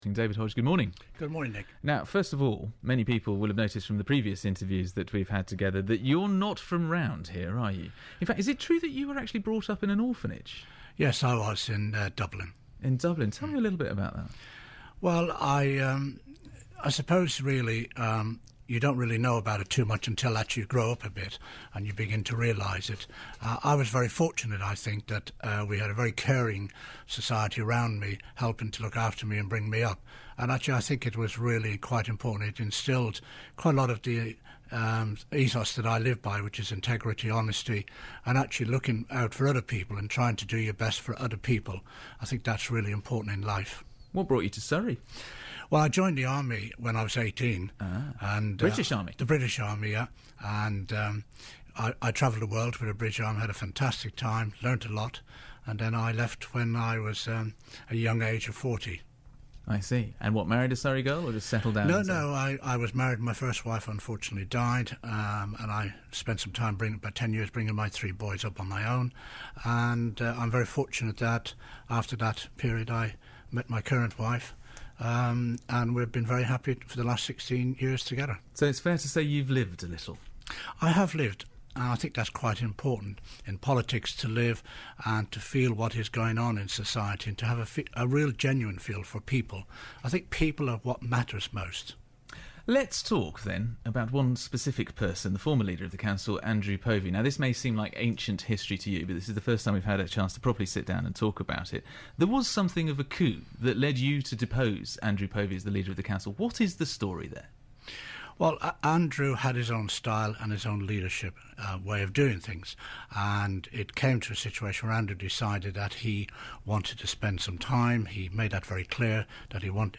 David Hodge, Leader of Surrey County Council, was interviewed on BBC Surrey’s breakfast show (02 December). Topics discussed included how his upbringing in a Dublin orphanage helped shape his approach to running the council.
david-hodge-bbc-iv.mp3